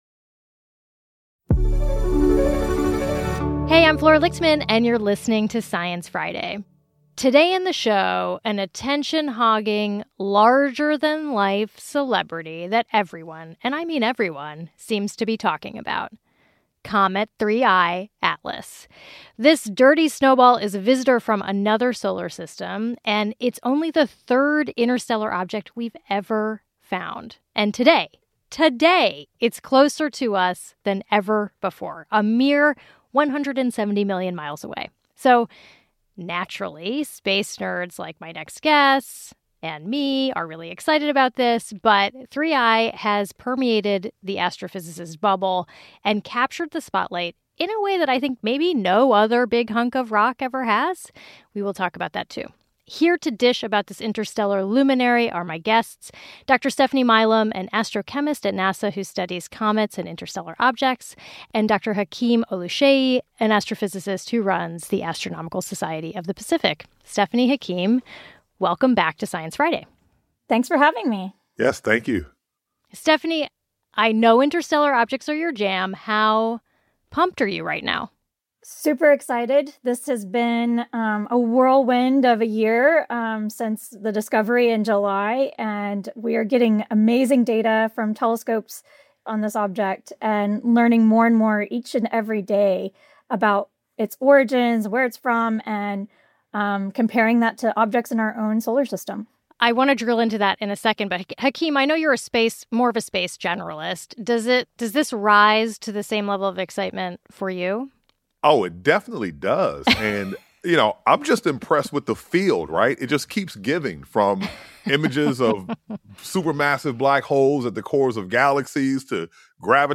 A listener pleads his case.